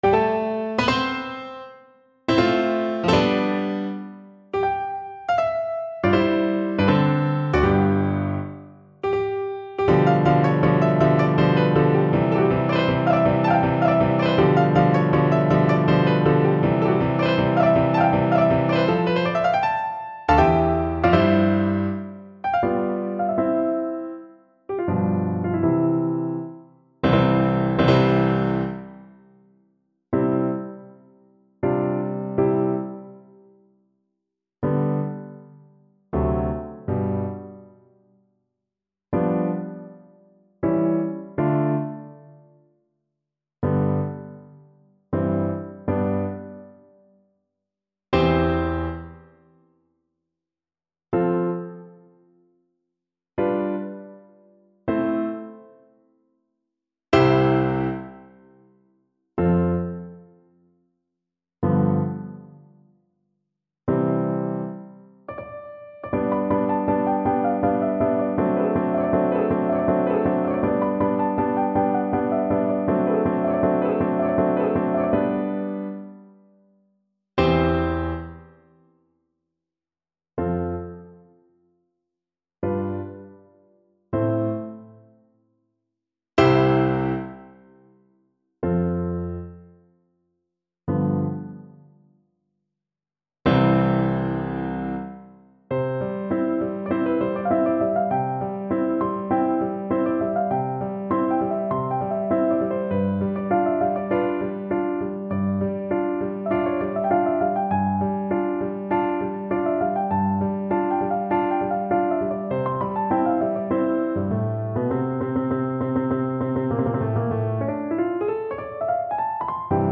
Allegro Moderato =80 (View more music marked Allegro)
Classical (View more Classical Flute Music)